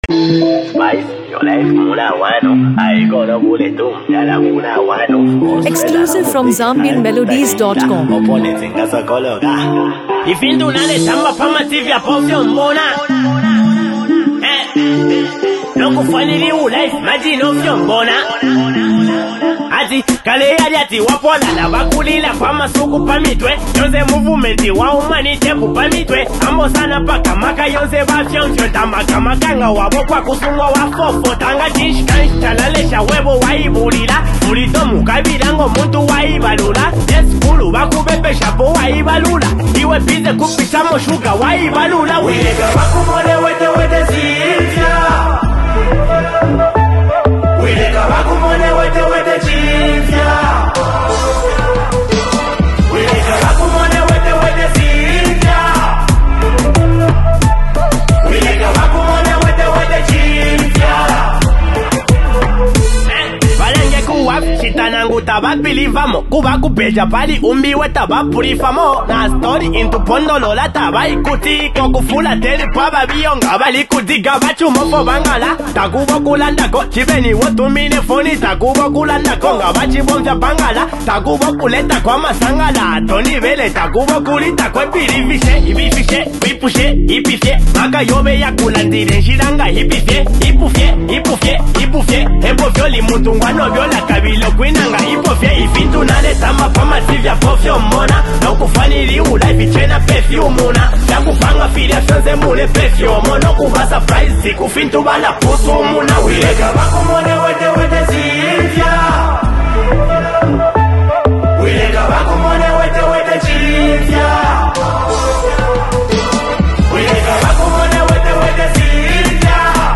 blends modern beats with cultural rhythms